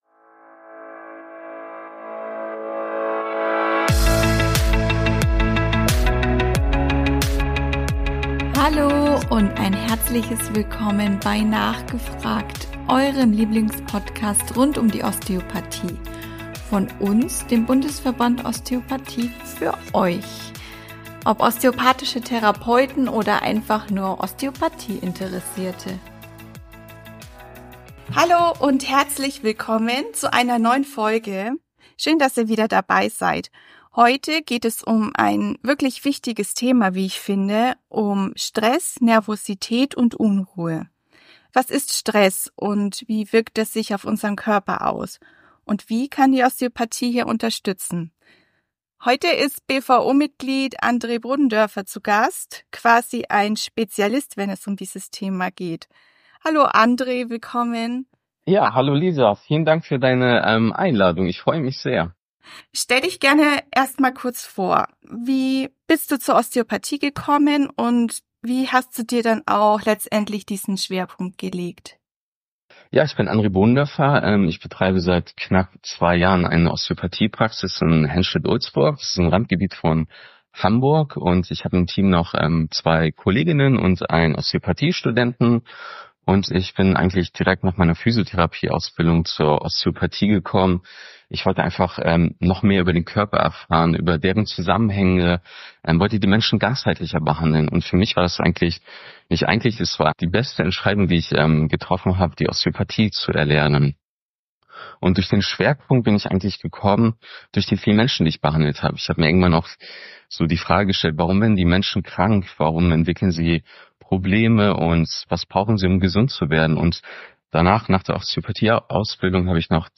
Folge 12 - Osteopathie bei Stress, Nervosität und Unruhe: Interview